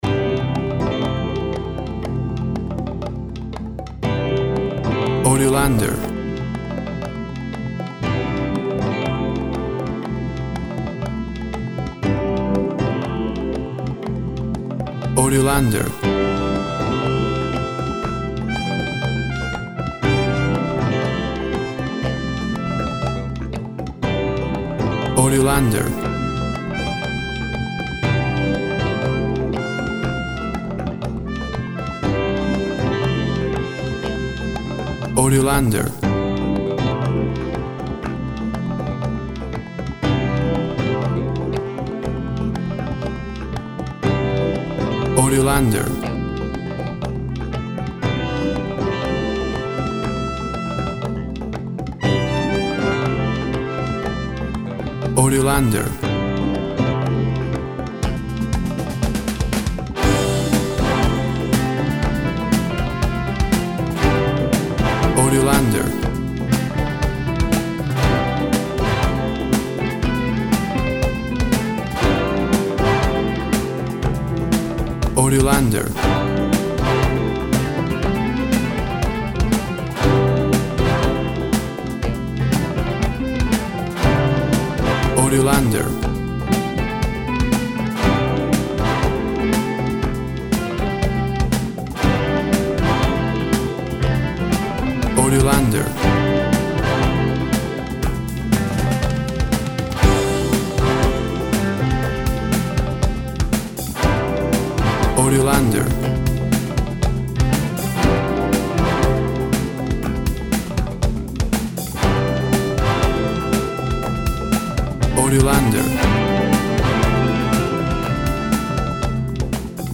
Tempo (BPM) 120